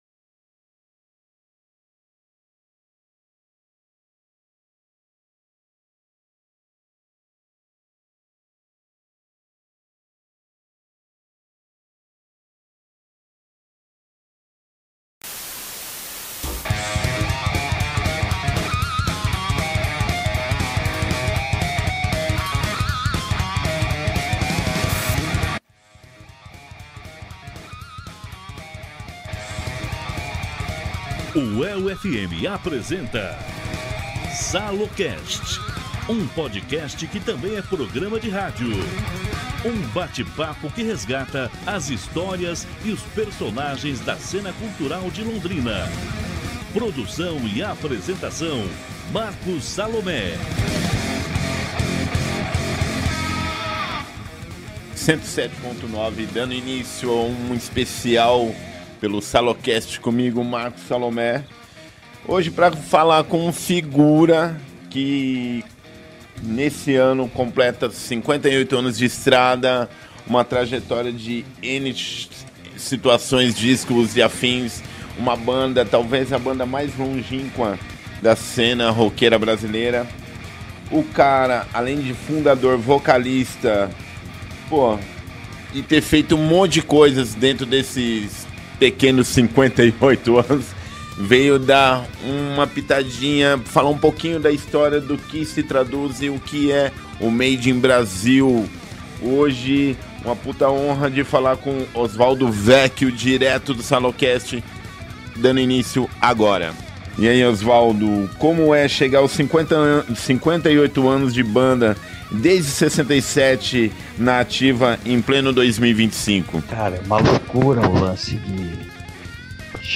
Salocast entrevista Oswaldo Vecchione, do Made In Brazil